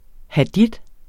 Udtale [ hɑˈdid ]